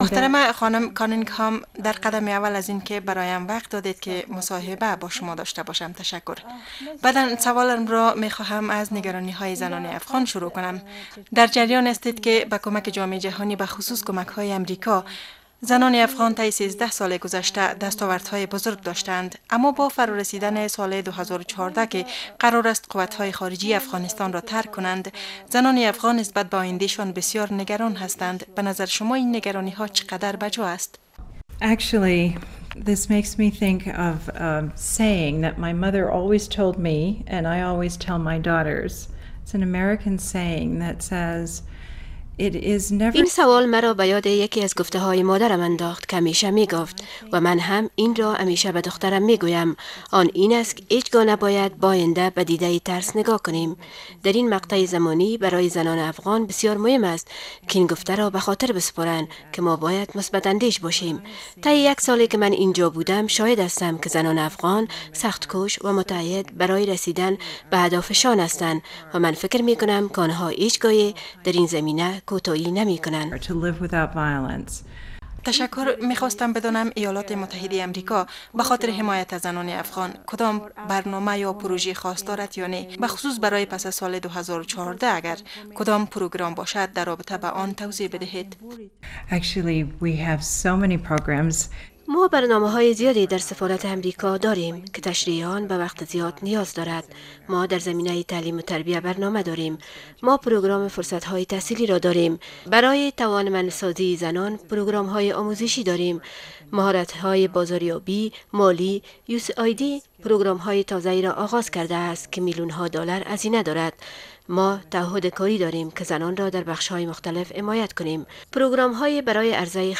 خانم کَنِنگهام، در یک مصاحبه ویژه با رادیوآشنا به مناسبت هشتم مارچ، روز همبستگی زنان گفت که یکی از اهداف آنان دسترسی تمام زنان افغان به خدمات صحی و تعلیم می باشد.
Interview with Mrs Ambassador